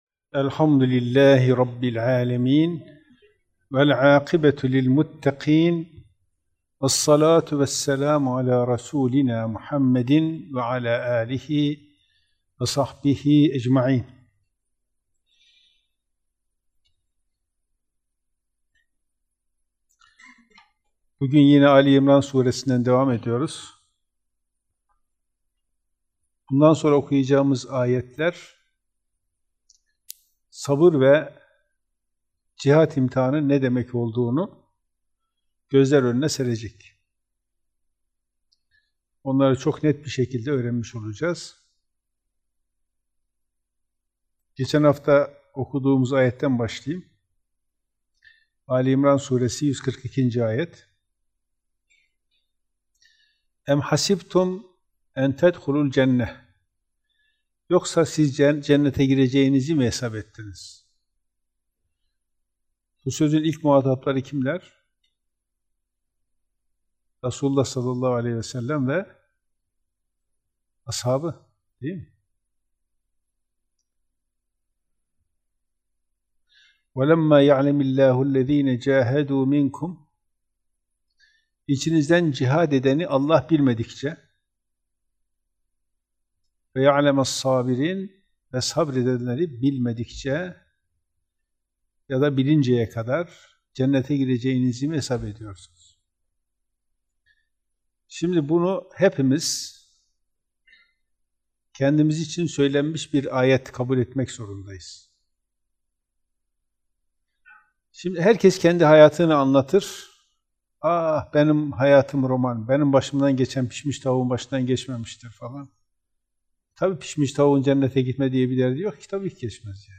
3.447 görüntülenme Kur'an Sohbetleri Etiketleri